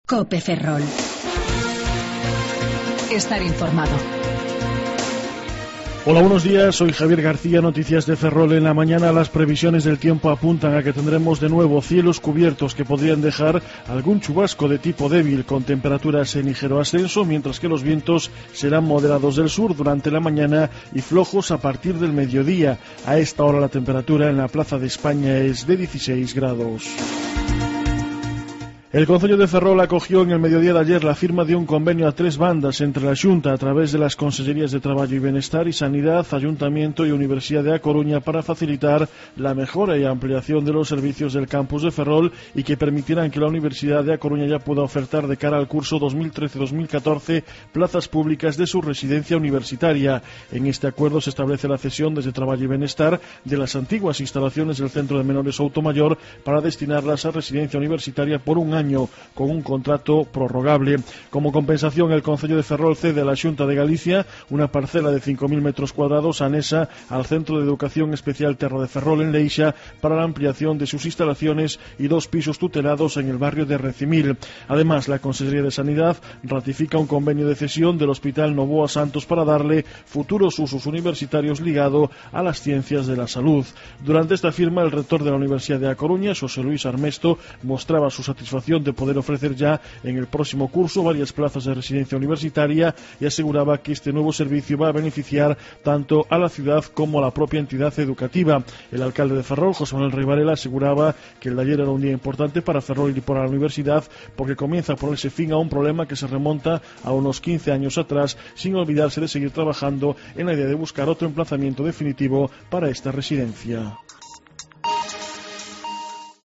08:28 Informativo La Mañana